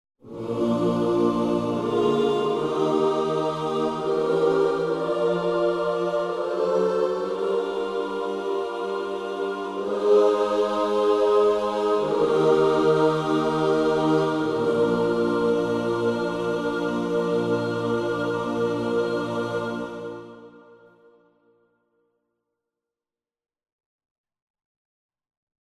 Gospel Oos p demo =1-C01.mp3